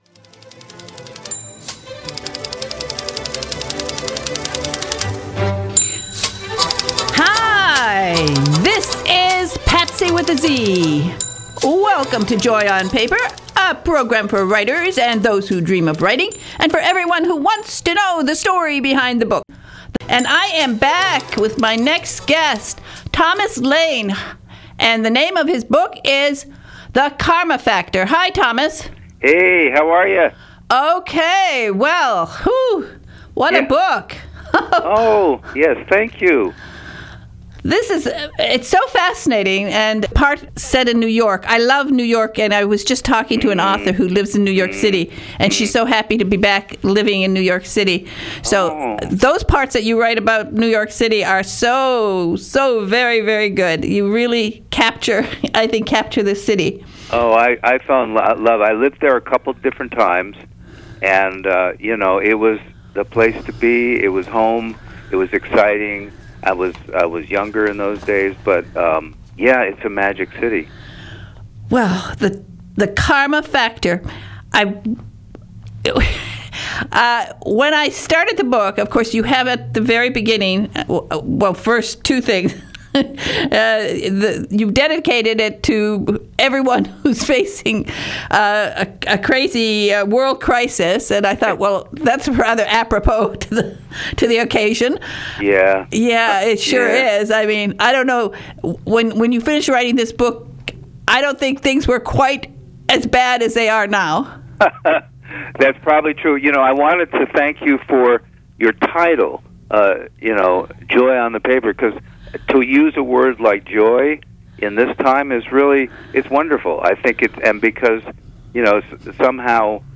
This is the first interview today